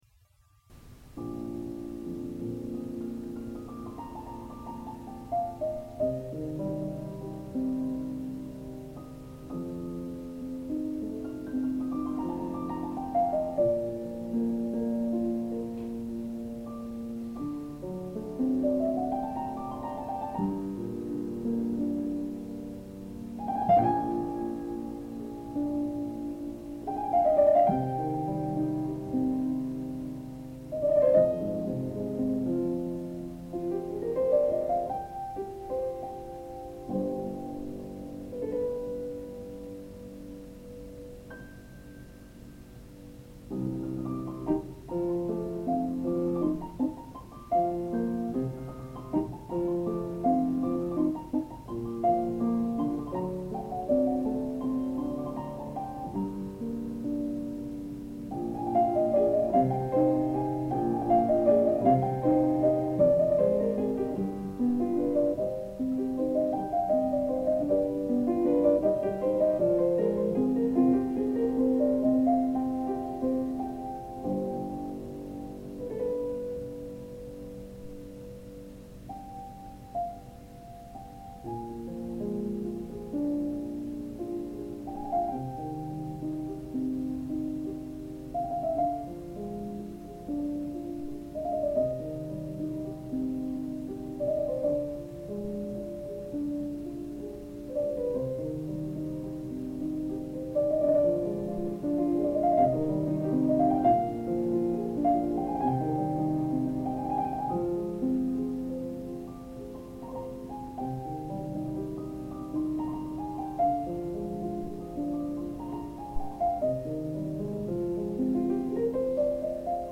interpretada en 1969 a la edad de 24 años en un concierto celebrado en el Hotel Marbella Hilton.